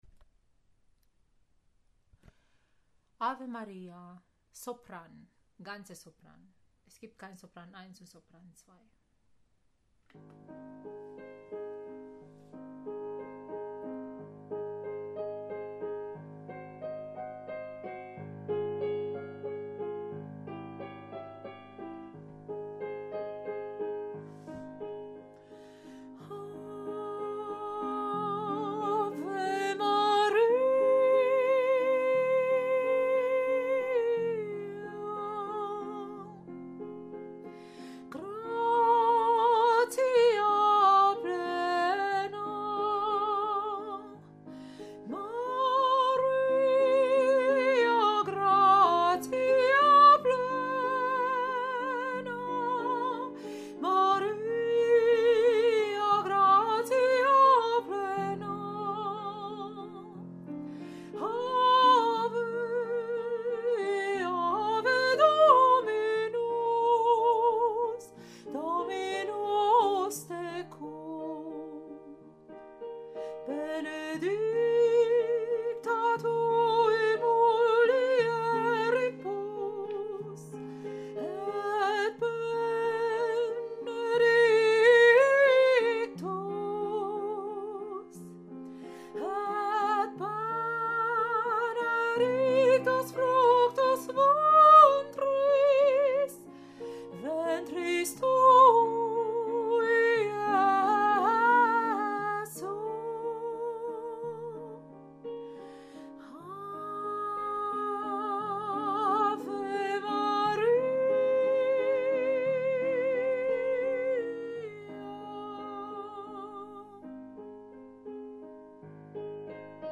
Ave Maria – Sopran 1